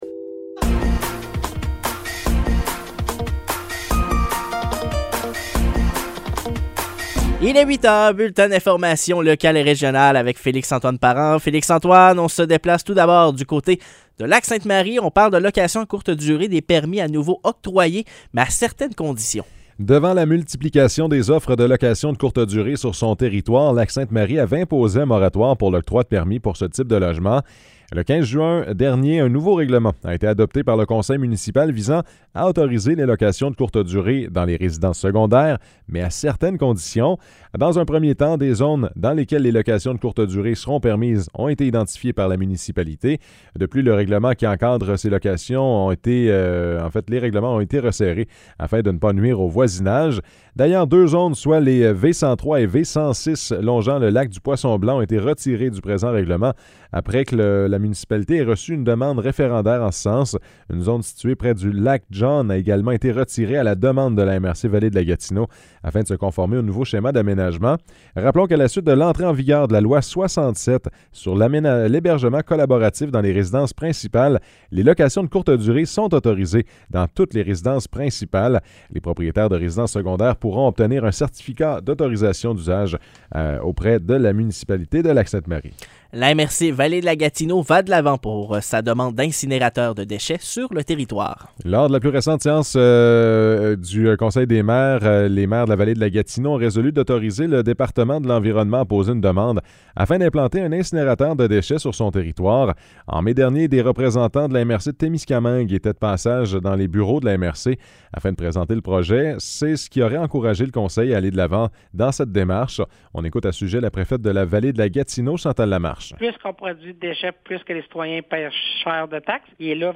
Nouvelles locales - 27 juin 2023 - 8 h